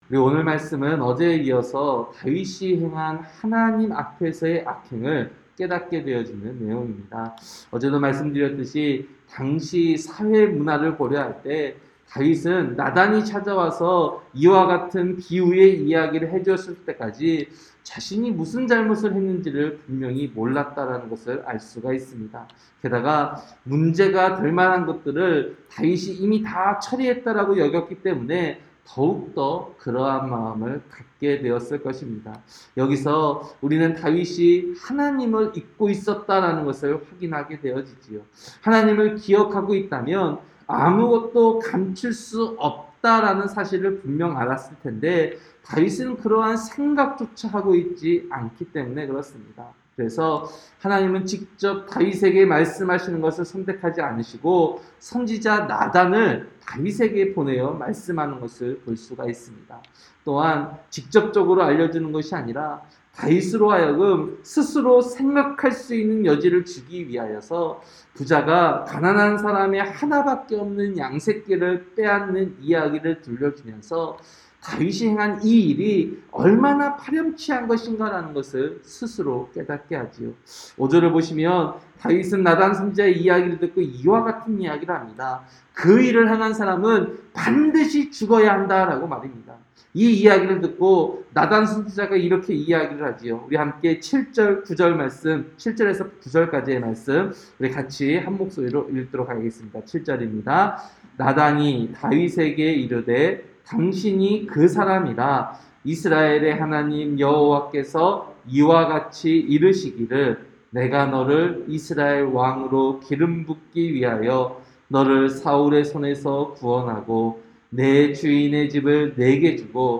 새벽설교-사무엘하 12장